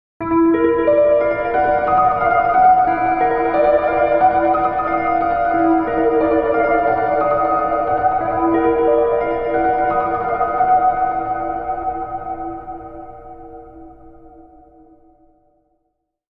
Sound Logo 4 Sep